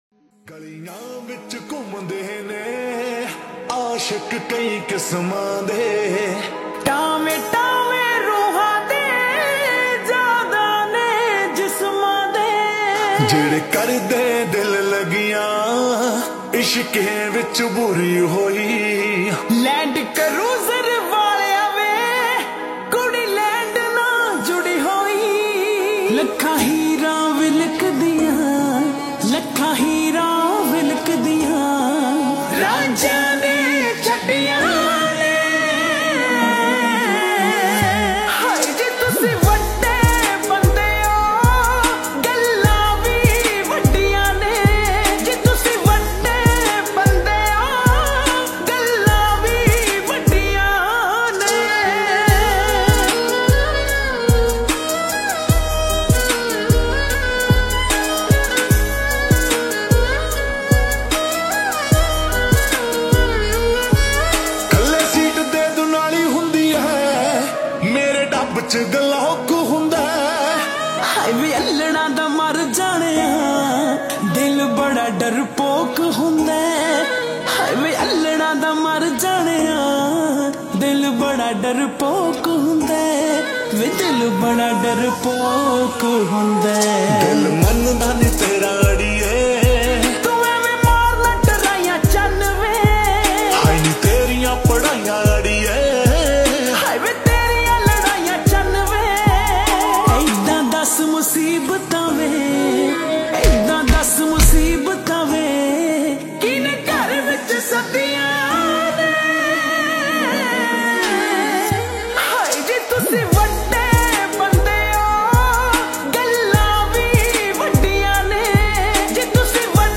Slow X Reverb